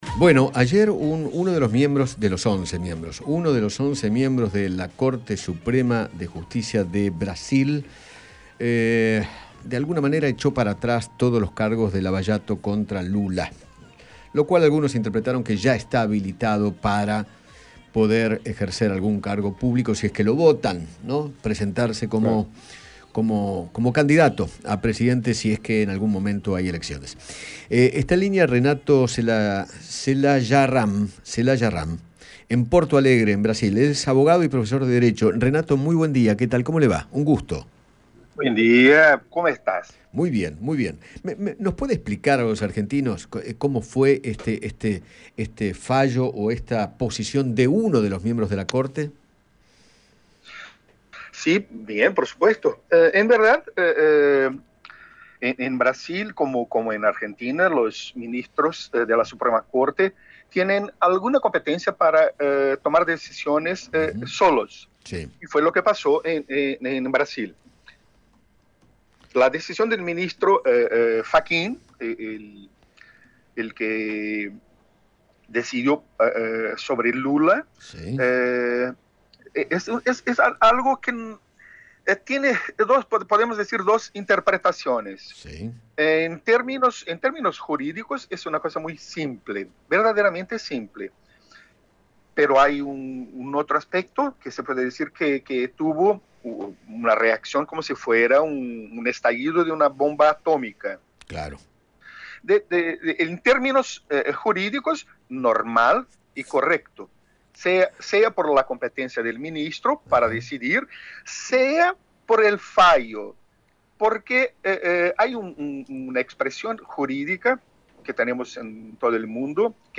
abogado y profesor de derecho en Brasil